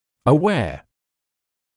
[ə’weə][э’уэа]осведомлённый, знающий; осознающий, понимающий